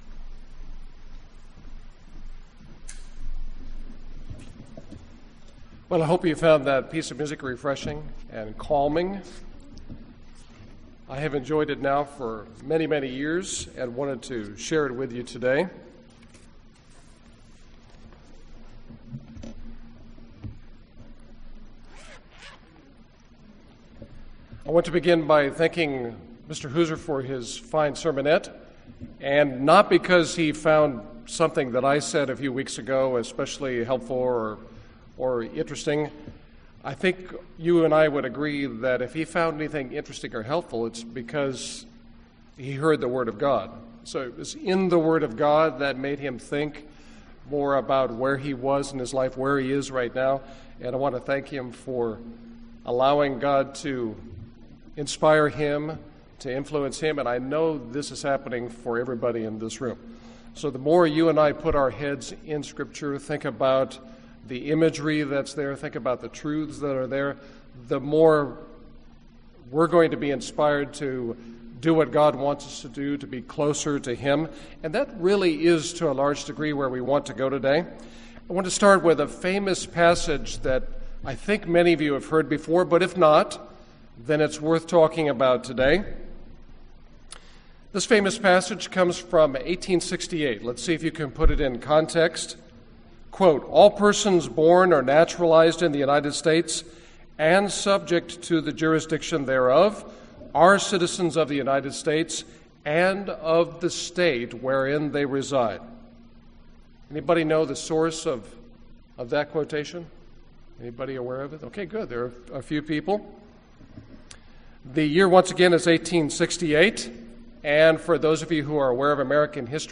UCG Sermon Studying the bible?
Given in Dallas, TX